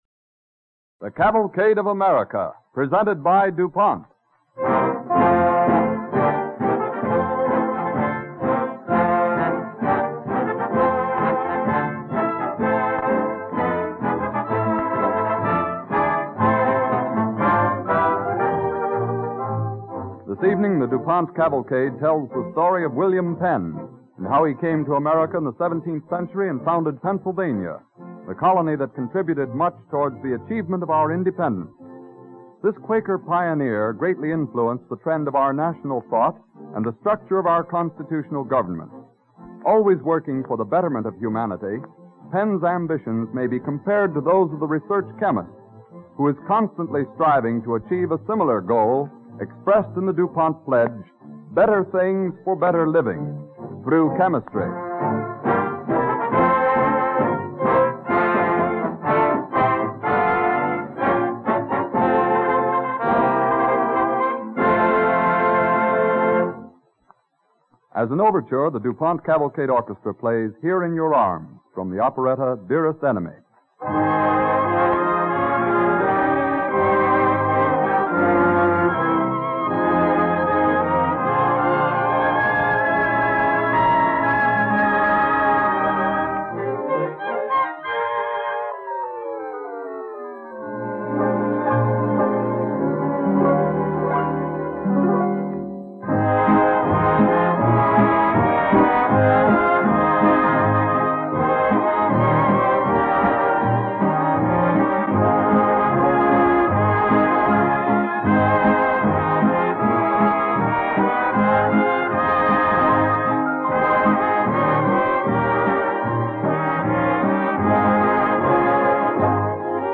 With announcer Dwight Weist